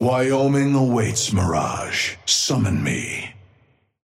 Amber Hand voice line - Wyoming awaits, Mirage. Summon me.
Patron_male_ally_mirage_start_01.mp3